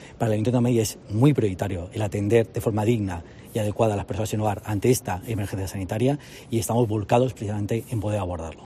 El concejal delegado del Área de Familias, Pepe Aniorte